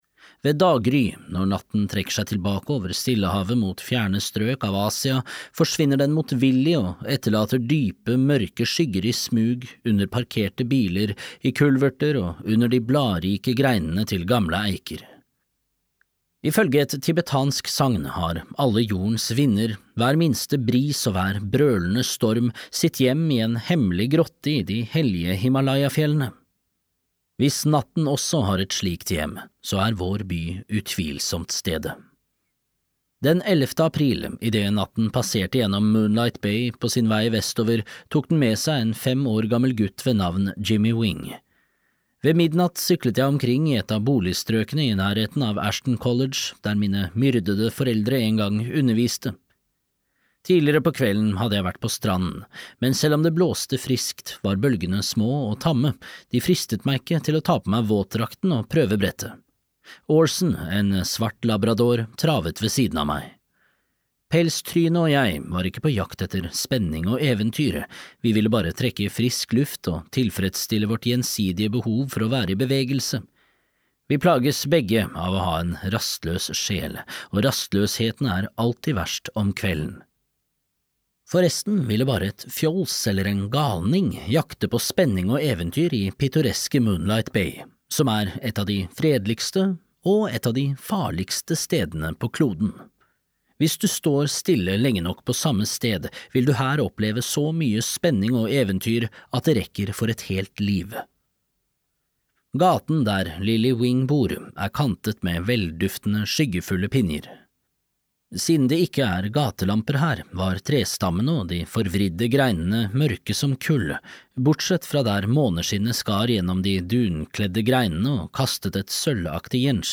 Grip natten (lydbok) av Dean R. Koontz